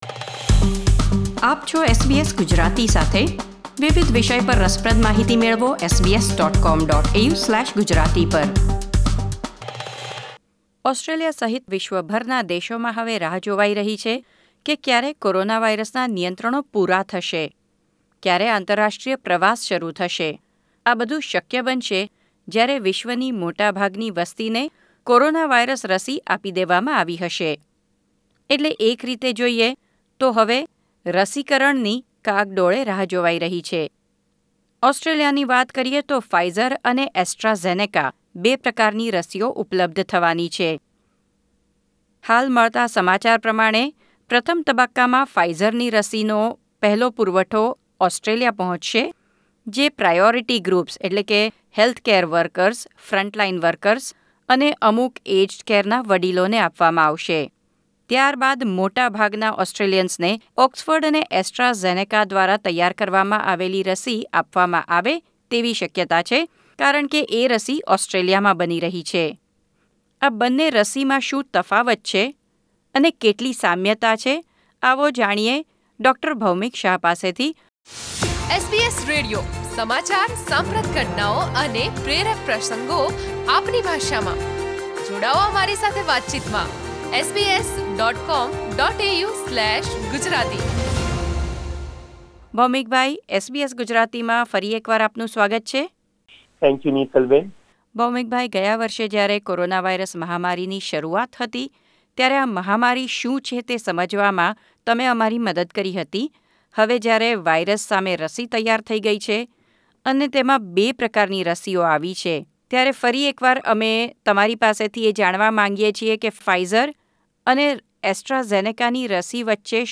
તેમણે બંને રસીની બનાવટ તથા પરીક્ષણની પ્રક્રિયા વિશે થયેલા વિવાદ અંગે પણ SBS Gujarati સાથેની વાતચીતમાં વિસ્તૃત માહિતી આપી હતી.